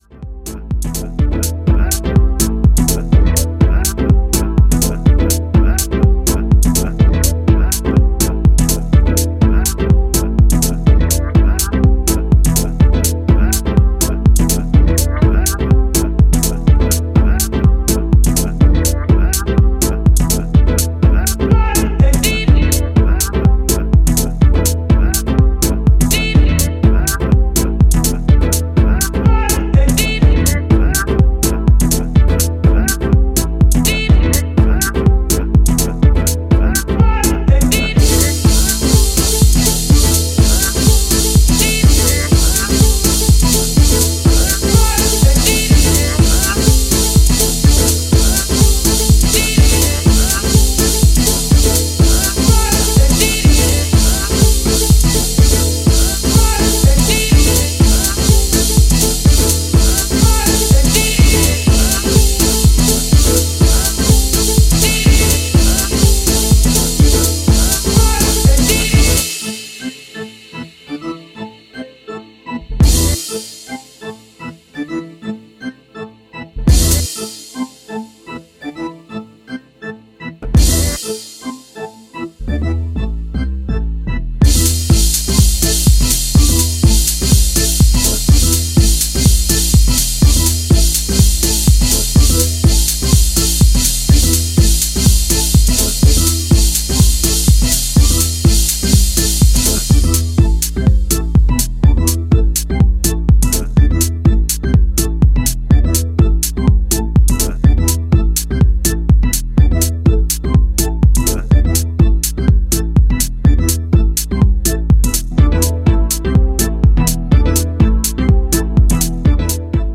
US house